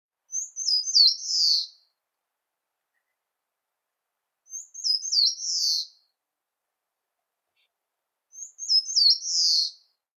センダイムシクイ｜日本の鳥百科｜サントリーの愛鳥活動
「日本の鳥百科」センダイムシクイの紹介です（鳴き声あり）。スズメよりずっと小さいムシクイの仲間の鳥。